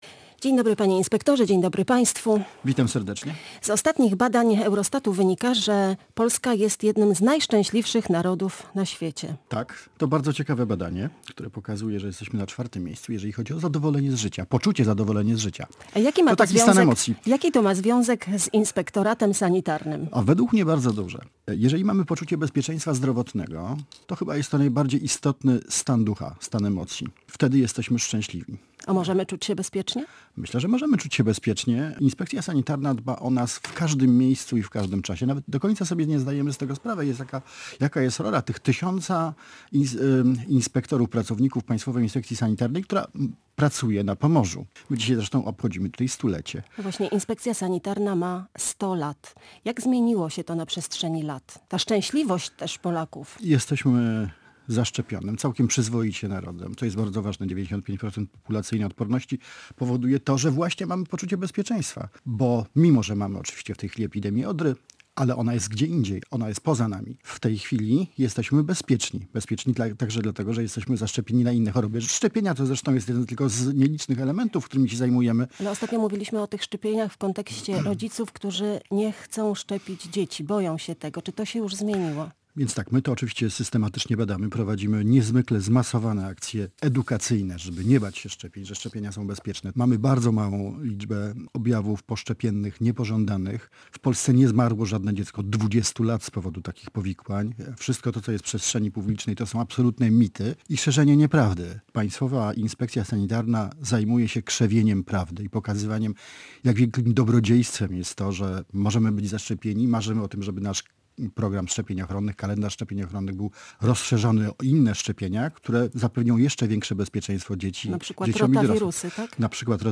Jaki to ma związek z Państwowym Inspektoratem Sanitarnym? Między innymi na to pytanie odpowiedział Gość Dnia Radia Gdańsk – Jarosław Pinkas, Główny Inspektor Sanitarny.